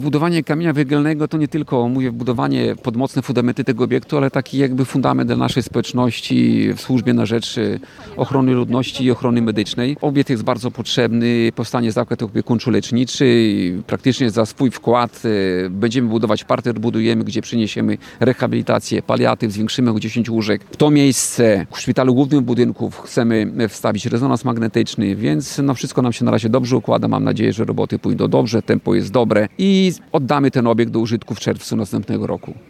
Starosta kolneński Tadeusz Klama podkreślił, że to najważniejsza inwestycja dla Powiatu.